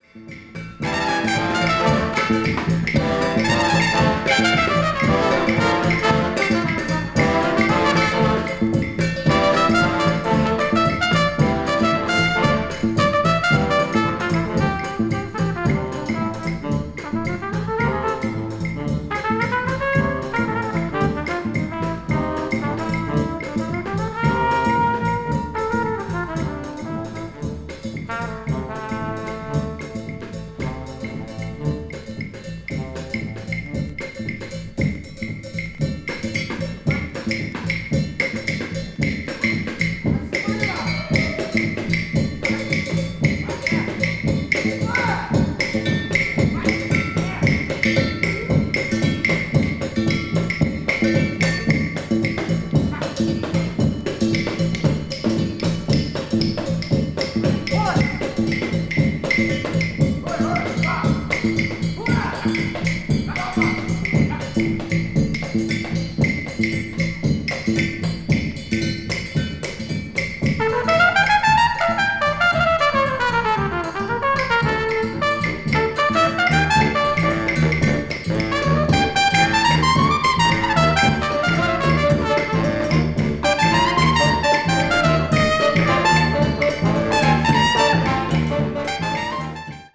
media : EX/EX(わずかにチリノイズが入る箇所あり)
afro cuban jazz   bop   hard bop   latin jazz   modern jazz